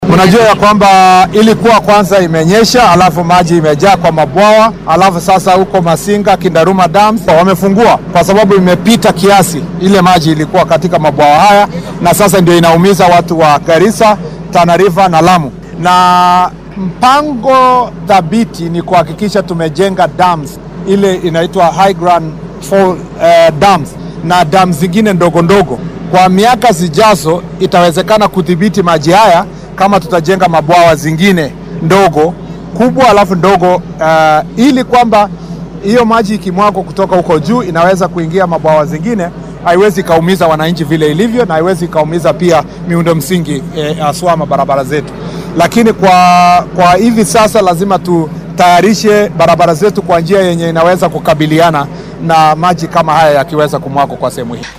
DHAGEYSO:Wasiirka gaadiidka oo ka hadlay xal u helidda daadadka Garissa, Tana River iyo Lamu